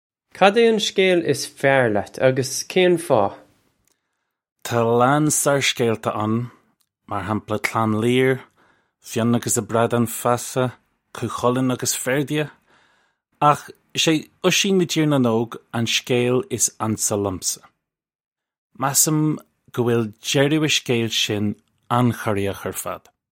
Agallamh le Seanchaí mír 4